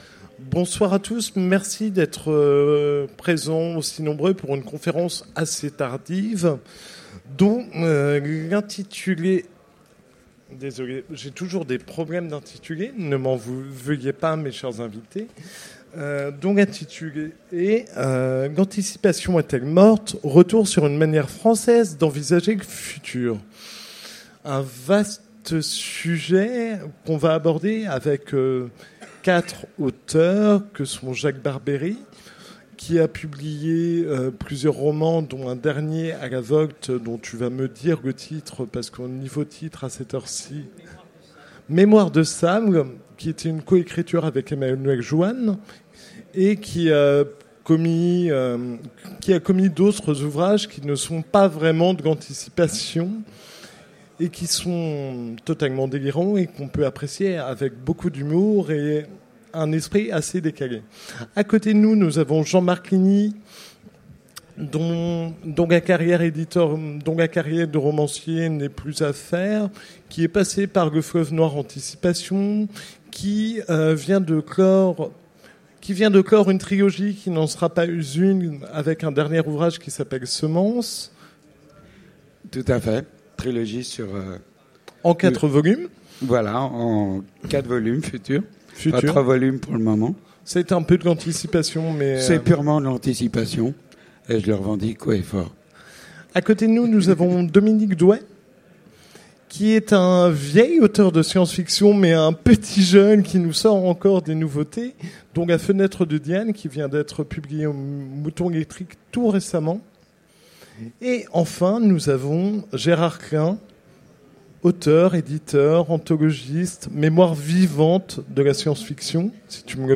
Utopiales 2015 : Conférence L’anticipation est-elle morte ?